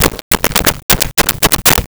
Switchboard Telephone Dialed 01
Switchboard Telephone Dialed 01.wav